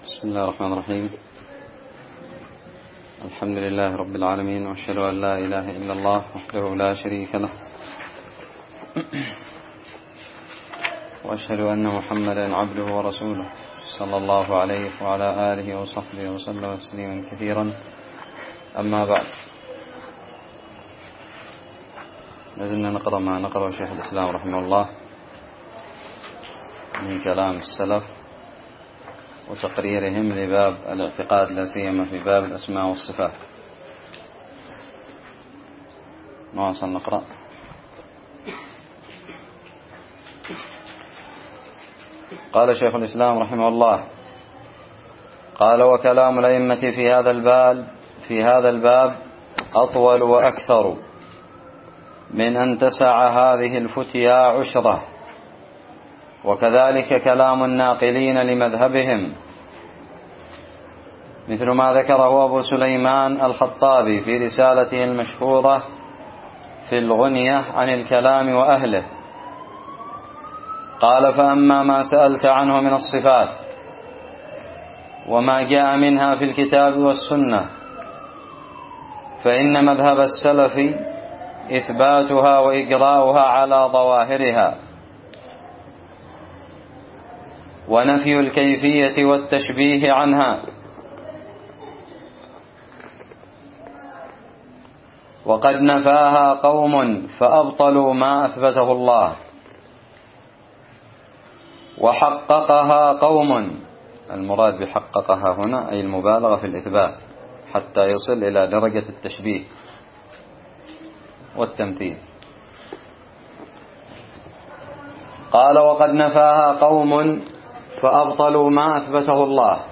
الدرس الحادي عشر من شرح متن الحموية
ألقيت بدار الحديث السلفية للعلوم الشرعية بالضالع